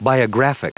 Prononciation du mot biographic en anglais (fichier audio)
Prononciation du mot : biographic